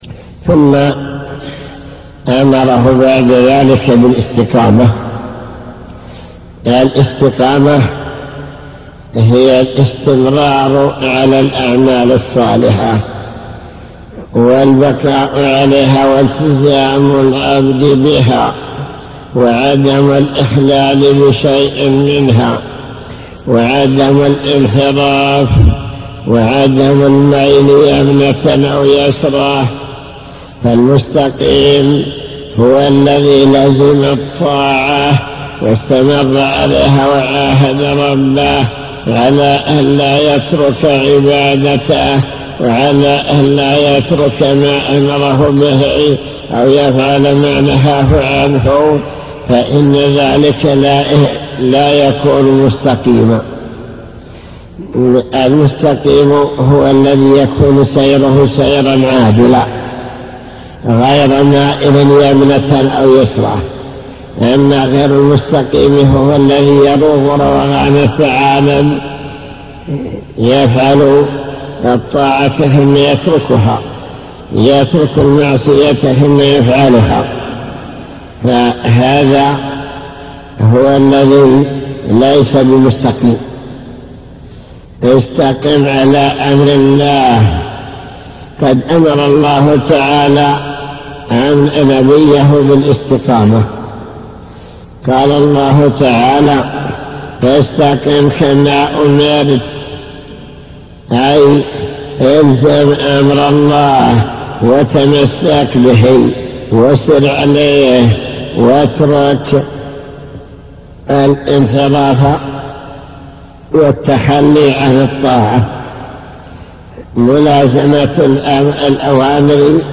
المكتبة الصوتية  تسجيلات - كتب  شرح كتاب بهجة قلوب الأبرار لابن السعدي شرح حديث قل آمنت بالله ثم استقم بشارة الله لمن آمن واستقام على طريقه